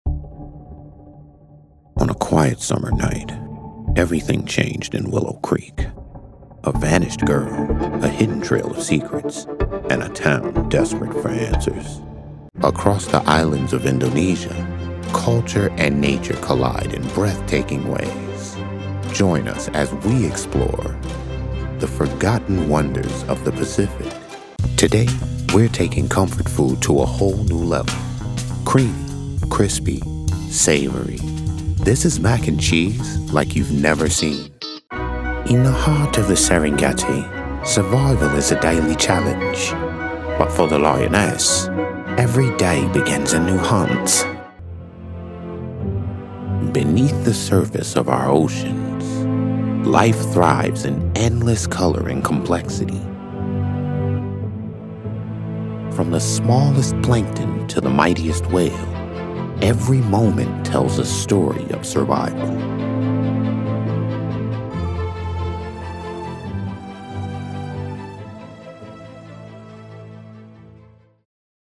Style in every syllable. Depth in tone.
In-Show Narration Demo